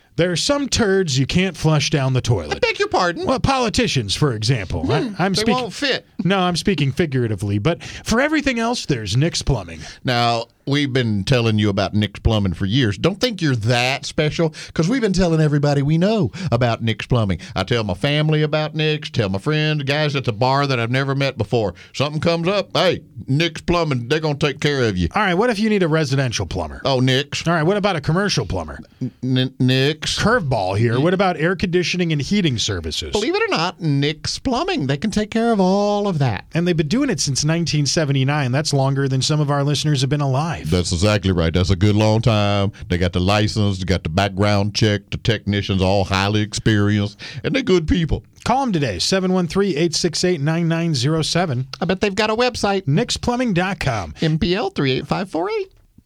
Nick’s Plumbing Radio Ads 51-100